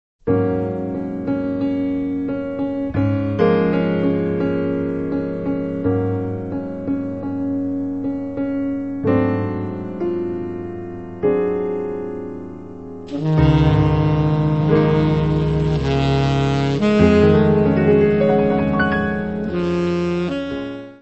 Descrição Física:  1 disco (CD) (53 min.) : stereo; 12 cm
Área:  Jazz / Blues